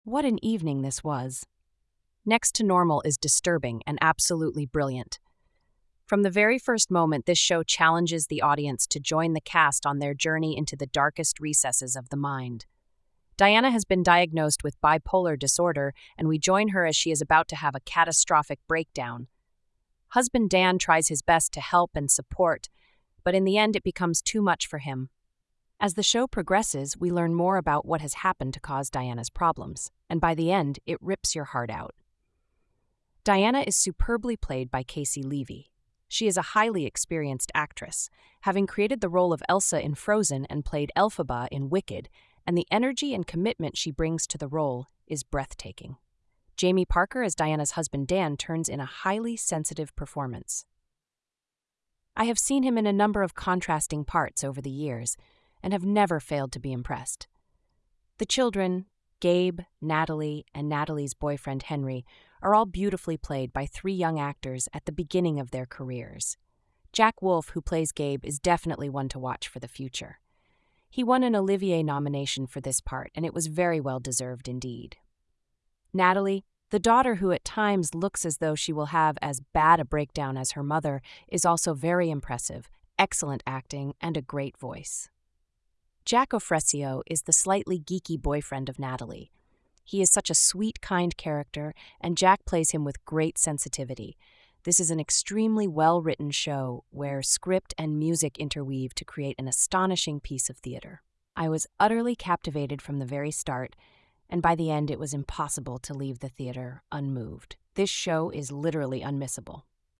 Next-to-Normal--Narration-.mp3